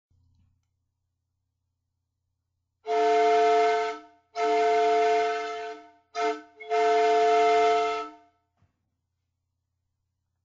Whistle 1-1/2″ dia. 12″ long – LocoParts
1 1/2″ diameter 12″ long Whistle. 4 Chime. Constructed of a brass tube over a cast brass core.
typical-12-inch-Whistle-1.wma